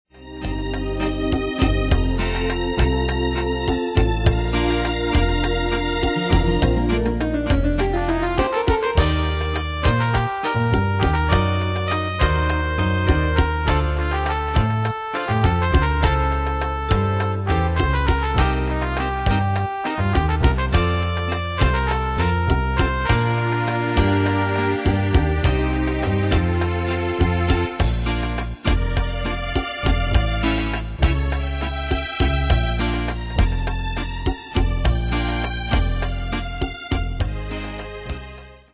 Style: Latino Ane/Year: 1991 Tempo: 102 Durée/Time: 3.24
Danse/Dance: Rhumba Cat Id.
Pro Backing Tracks